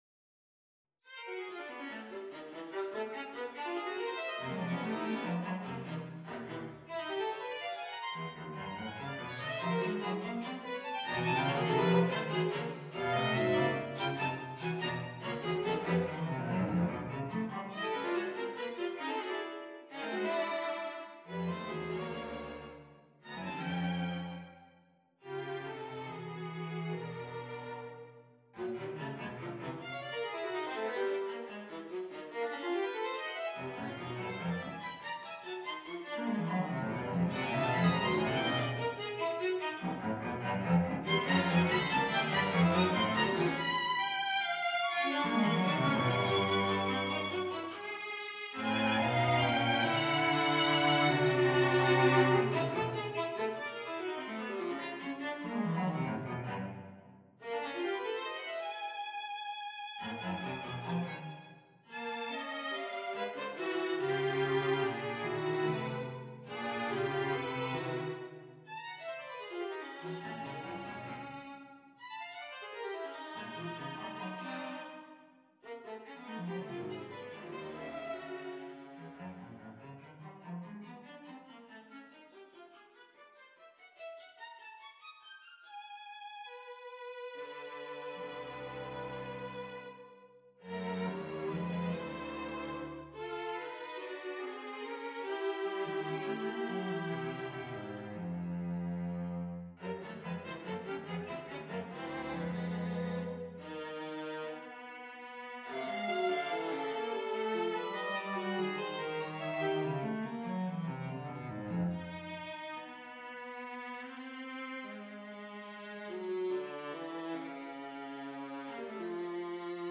on a purpose-selected tone row
Every thirty seconds, the tonal center shifts (and the meter changes): C-Bb-Gb-Ab-F-D-B-Db-A-G-Eb-E. Dedicated to the memory of my mother, who would have been 110 today - and doubtless still would not have understood why I insist on writing such bizarre and difficult music.